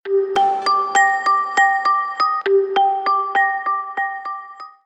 Звуки на СМС, рингтоны и звонки на SMS